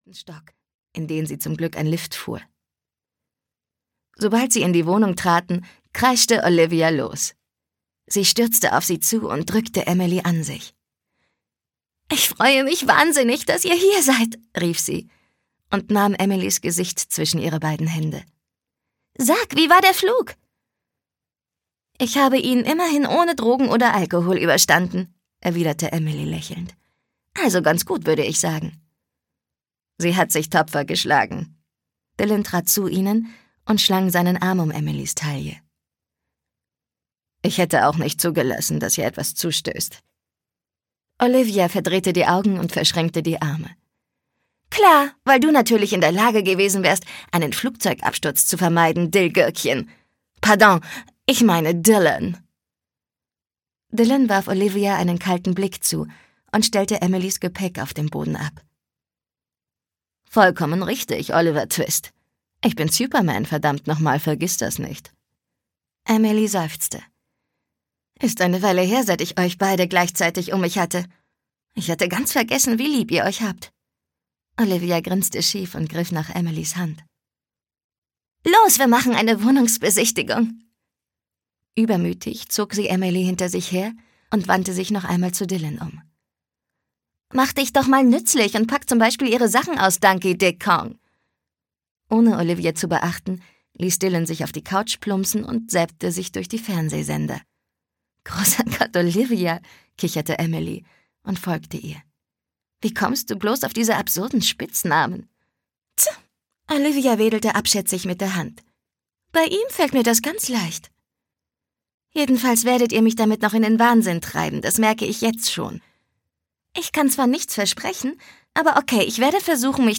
Collide-Serie 1: Collide - Unwiderstehlich - Gail McHugh - Hörbuch